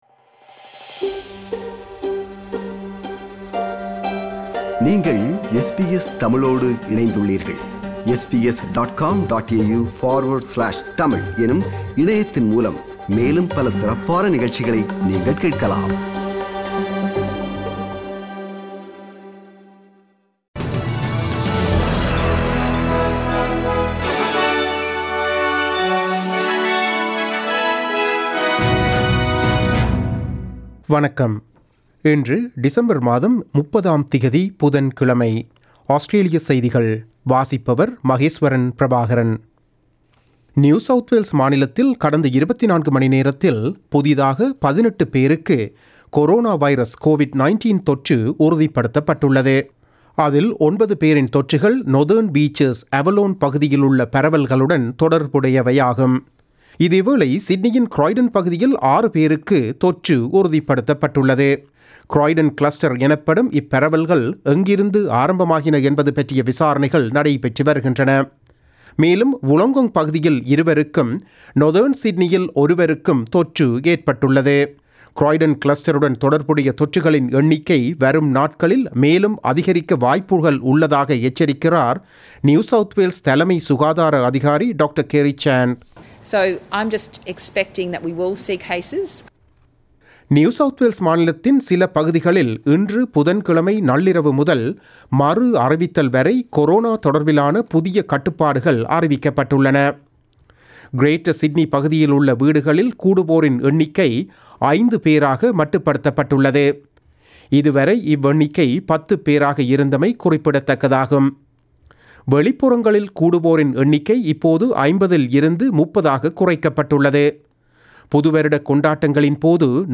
Australian news bulletin for Wednesday 30 December 2020.